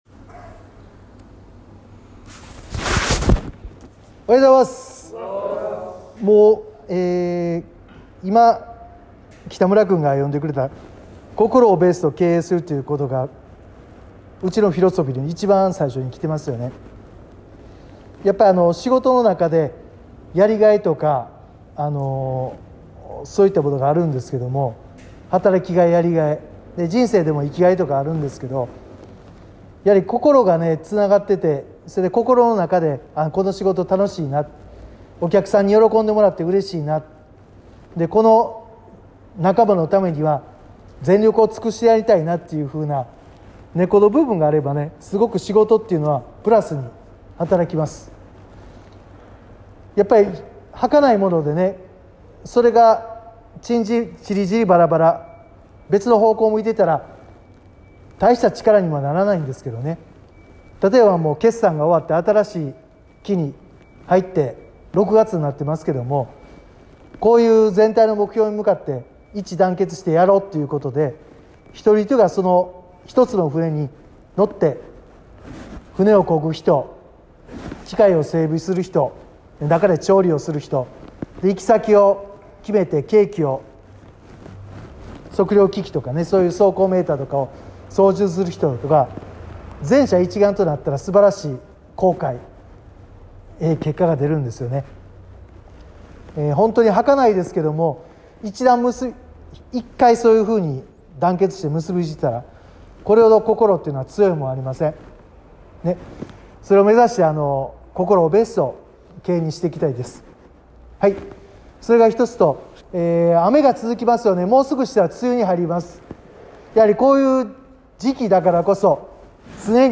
社長VOICE